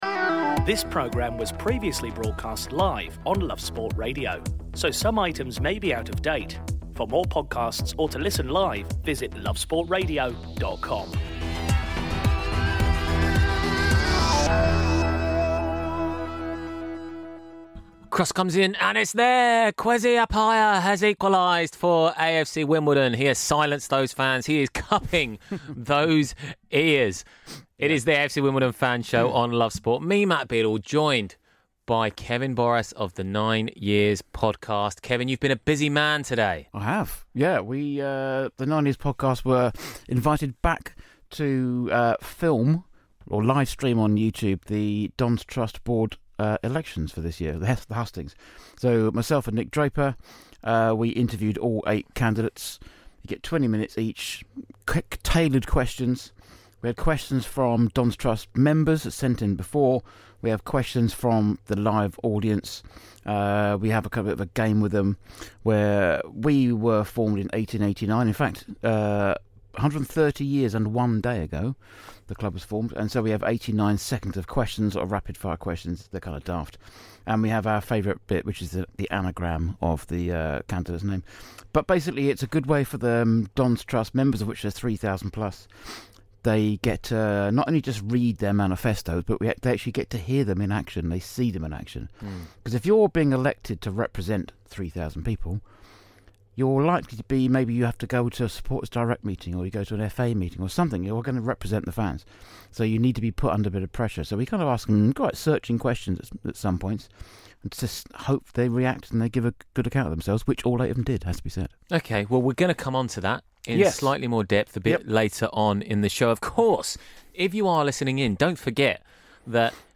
with a catchy jingle to boot